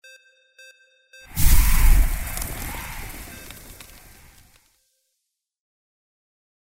Stereo sound effect - Wav.16 bit/44.1 KHz and Mp3 128 Kbps
previewSCIFI_ICE_FLAME_WBHD01B.mp3